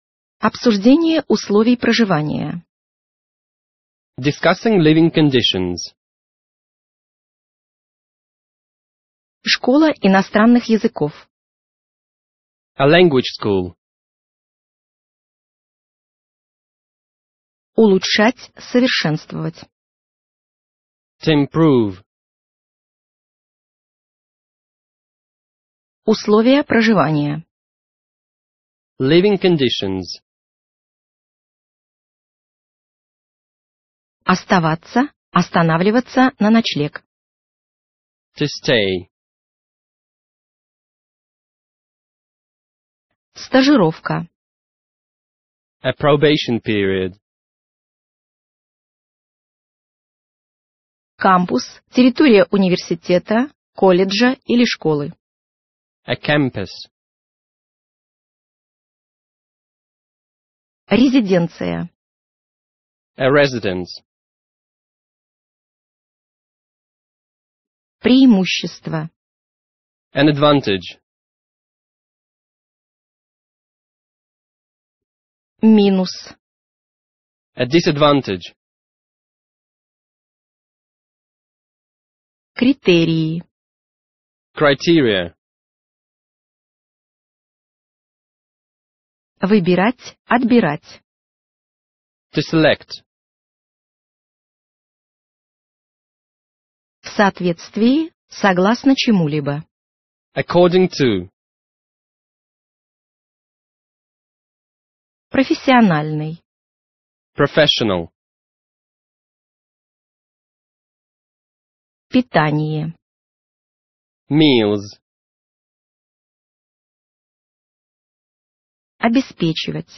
Аудиокнига Английский язык для студентов | Библиотека аудиокниг
Aудиокнига Английский язык для студентов Автор Коллектив авторов Читает аудиокнигу Профессиональные дикторы.